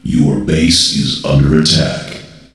voc_base_attack.ogg